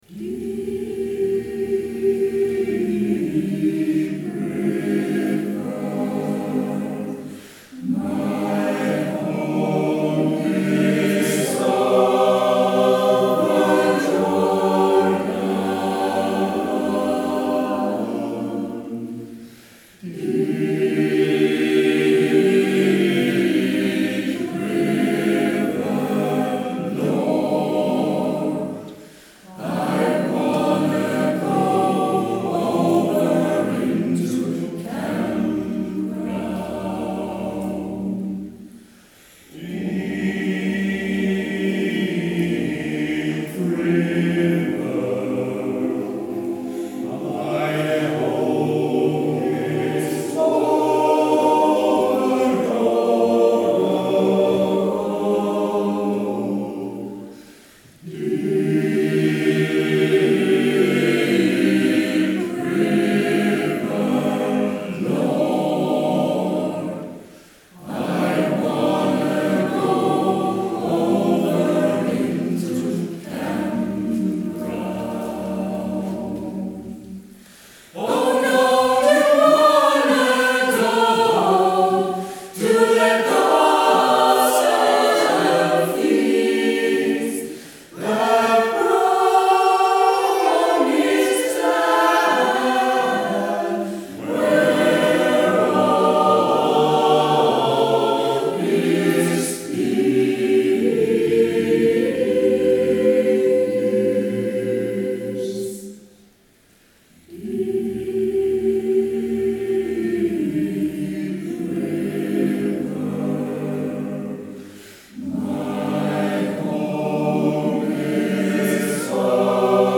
Deep River, en av de mest äskade och kända av alla afro-amerikanska spirituals. Texten hämtades ur Bibeln men tolkas på många sätt.